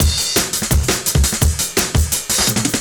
cw_170_Error.wav